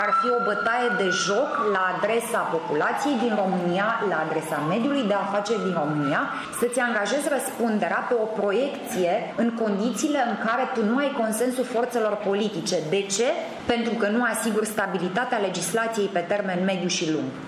Liberalii exclud însă posibilitatea adoptării noului Cod Fiscal prin asumarea răspunderii Guvernului şi doresc dezbaterea actului normativ în legislativ. Copreşedintele PNL Alina Gorghiu: